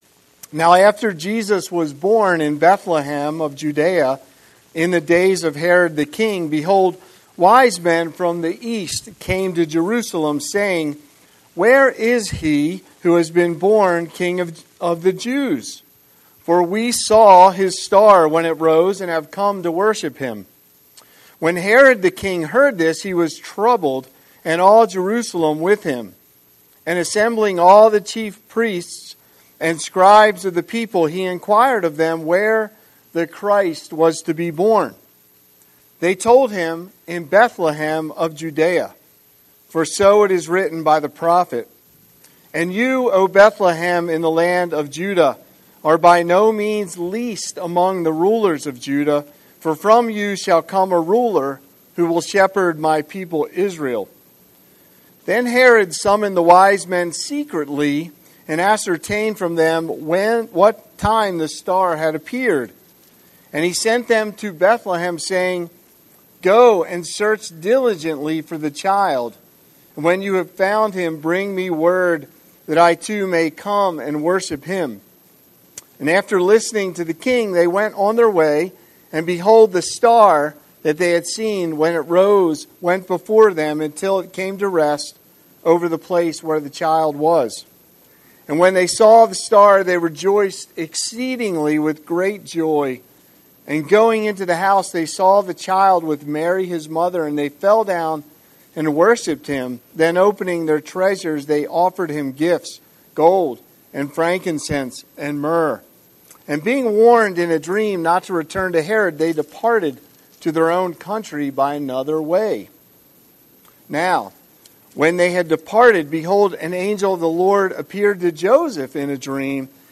Scripture: Matthew 2:1–18 Series: Sunday Sermon